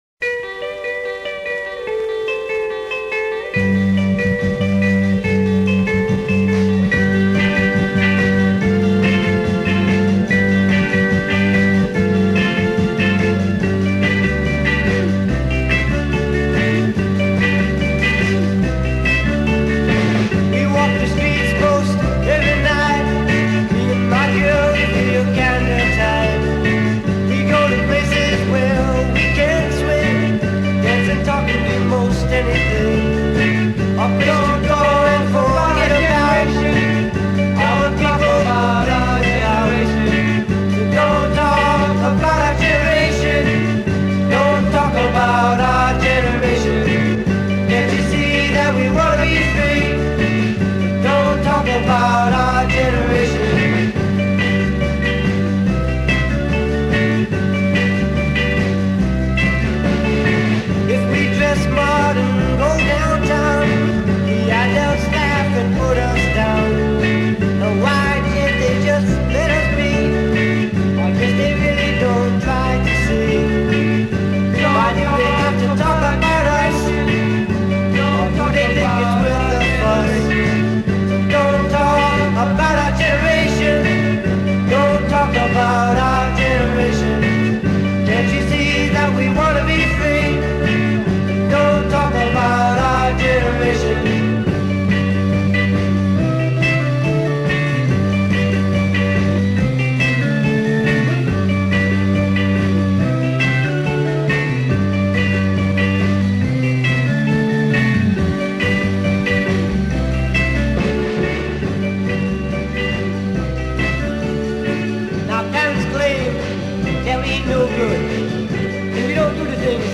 30 Killer Garage Rock Winners From 1965-1968
(one-sided acetate)